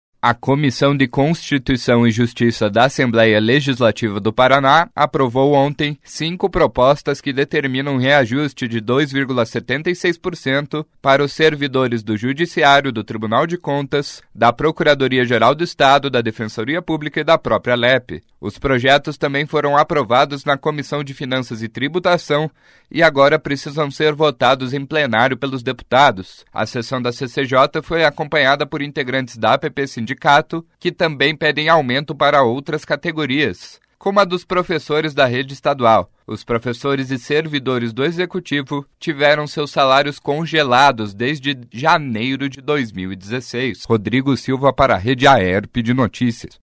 06.06 – BOLETIM SEM TRILHA – Reajuste de servidores do Judiciário e Legislativo está pronto para ir a plenário na Alep